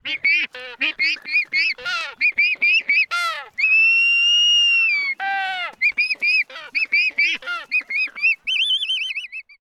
クロアシアホウドリ｜日本の鳥百科｜サントリーの愛鳥活動
「日本の鳥百科」クロアシアホウドリの紹介です（鳴き声あり）。
kuroashiahoudori_s.mp3